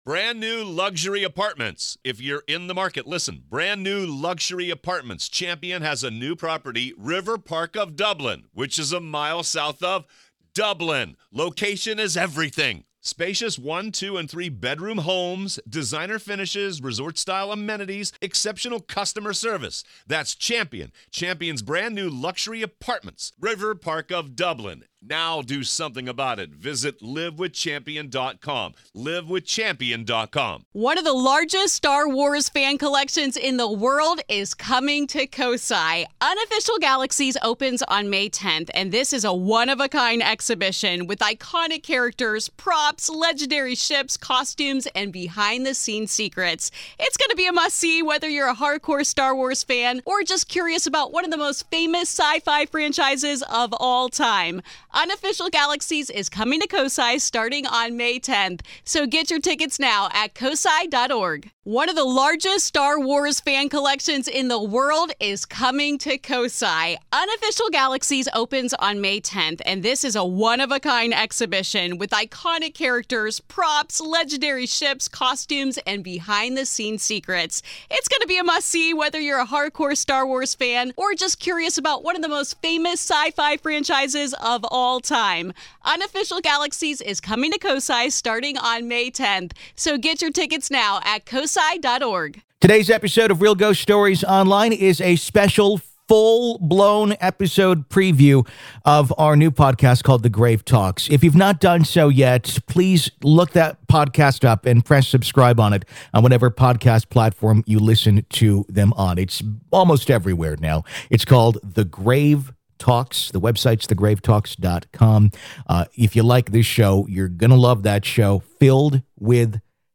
New 2018 Interview